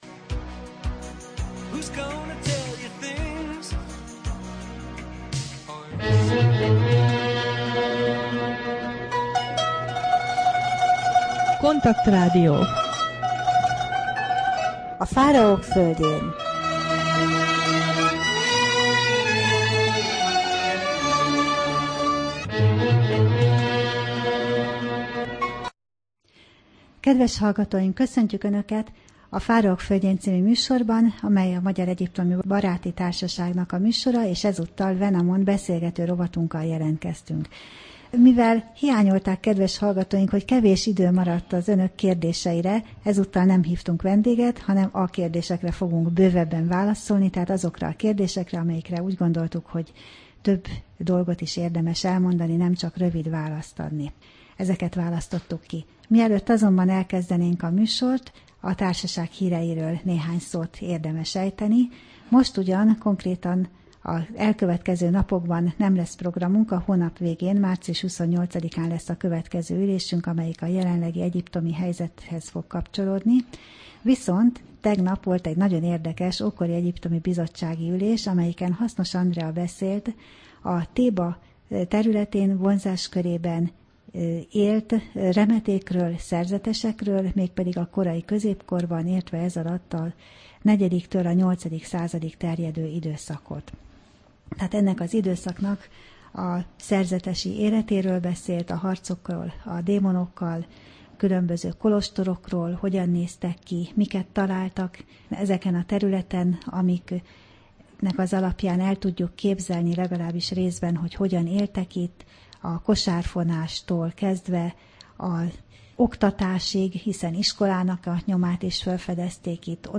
Rádió: Fáraók földjén Adás dátuma: 2011, March 11 Fáraók földjén Wenamon beszélgető rovat / KONTAKT Rádió (87,6 MHz) 2011. március 11.